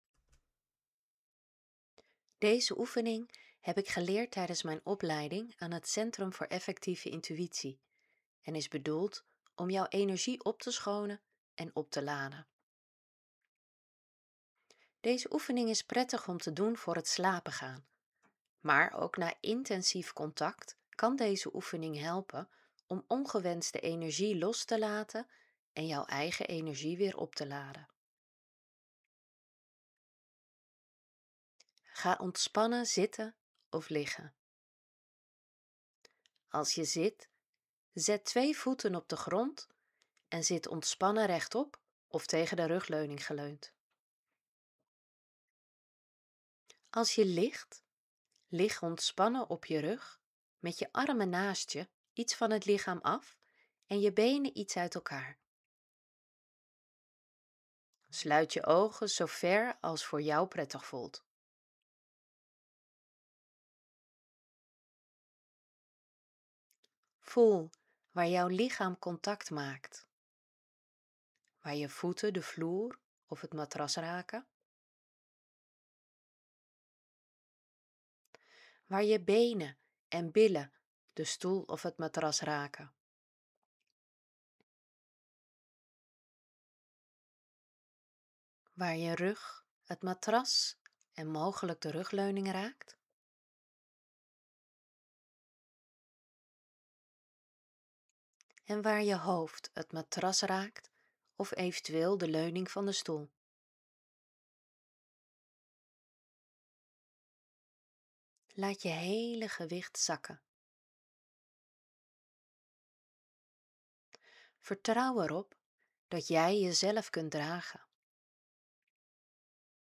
Meditatie Energie opschonen | Praktijk Sensitief Zijn